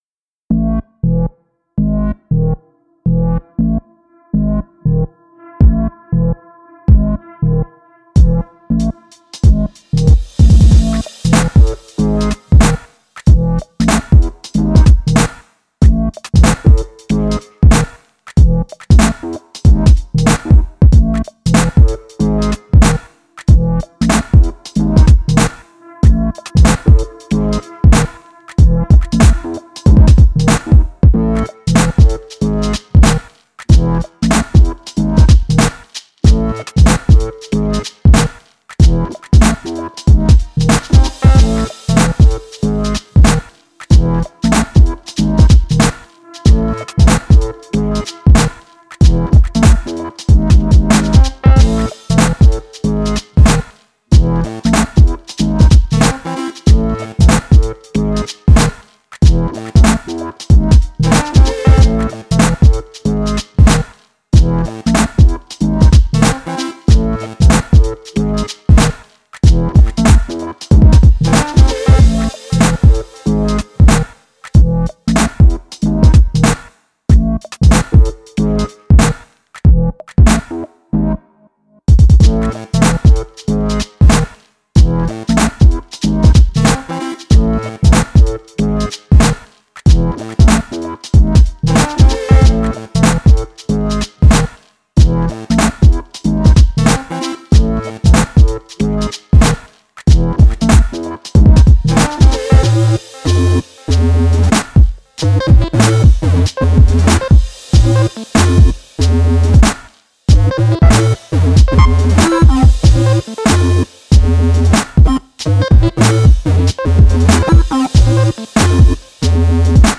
Twisted oddball beat with quirky synth noises.